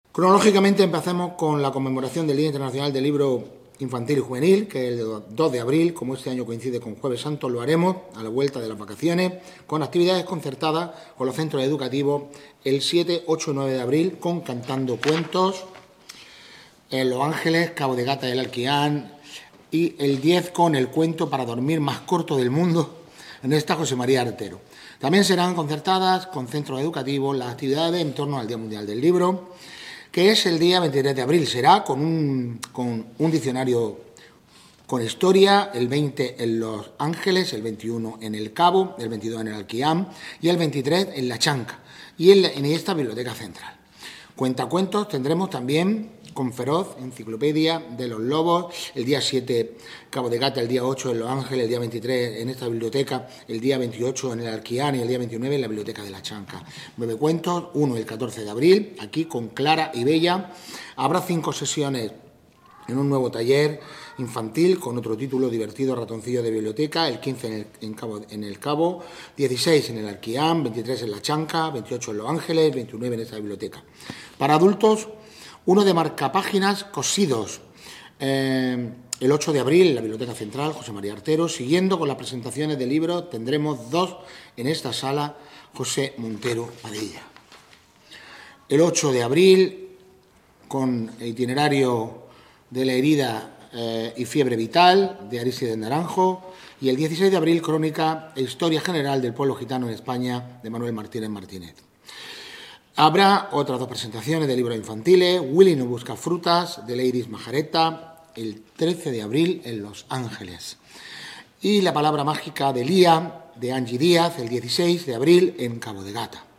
El concejal de Cultura, Diego Cruz, ha informado de la programación mensual que tendrá presentaciones de libros, cuentacuentos, talleres y numerosos actos concertados con los centros educativos